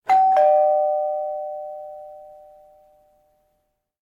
doorbell2.ogg